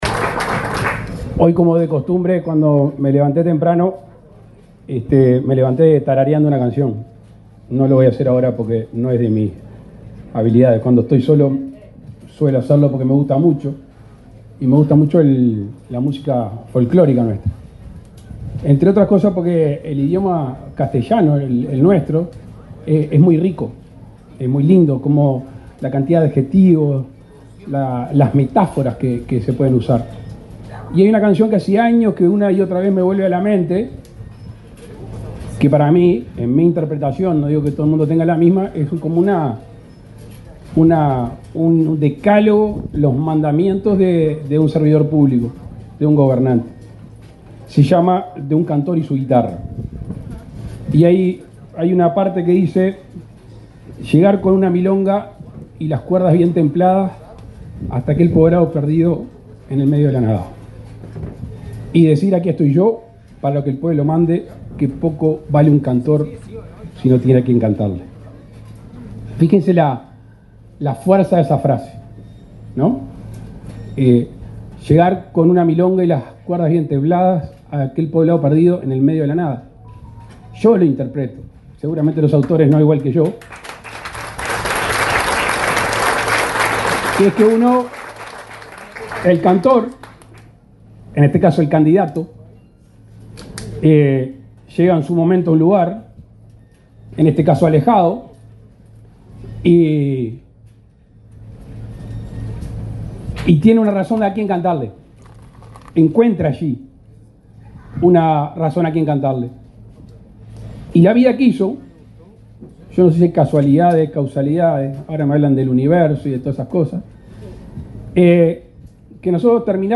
Palabras del presidente Luis Lacalle Pou